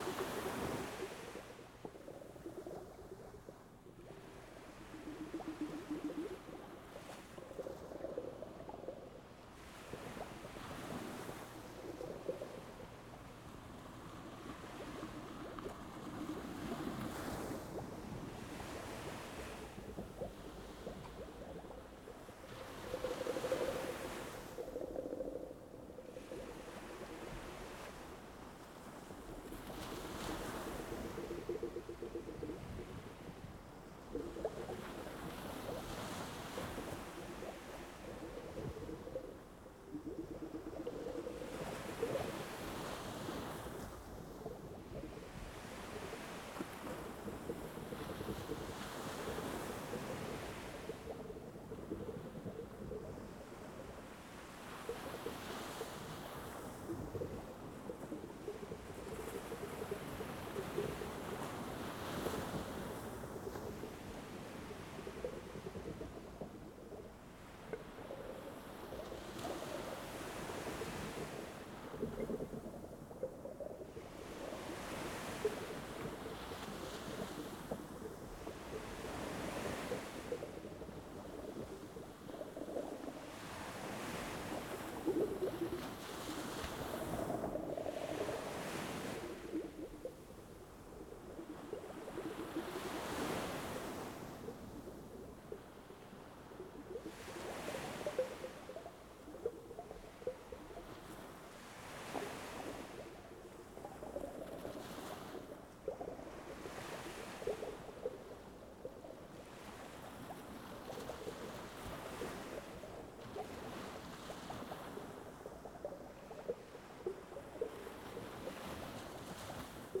Stream River Water Up Close
Perfect for ambience, ambient, babbling.